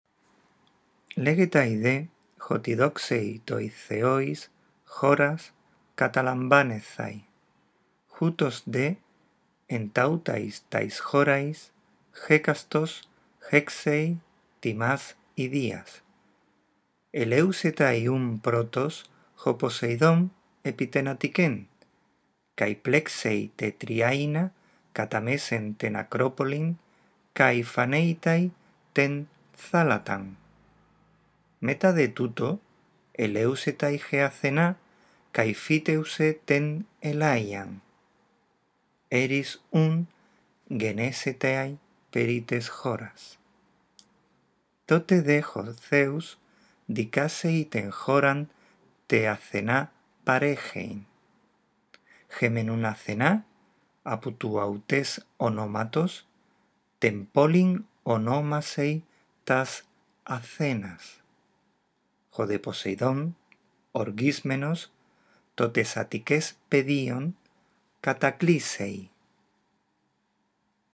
Lee en voz alta el texto sobre la disputa entre Poseidón y Atenea; después escucha este archivo de audio y corrige tu pronunciación.